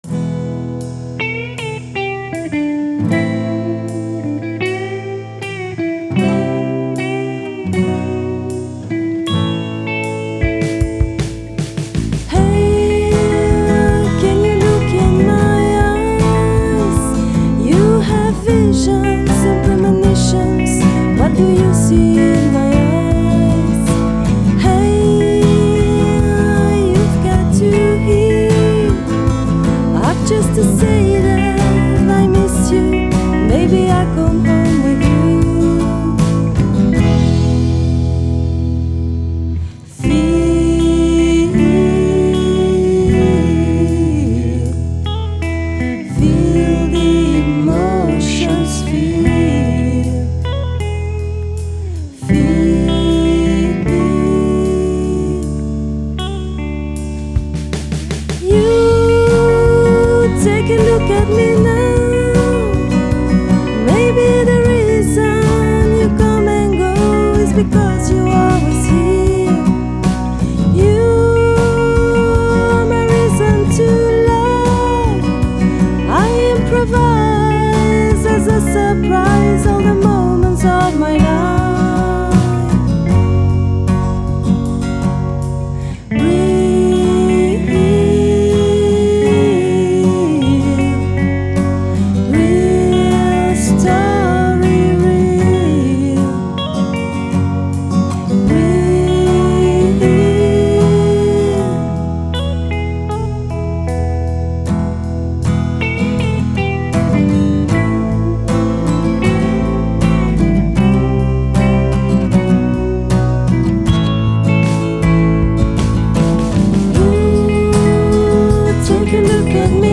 Guitares
Basse
Batterie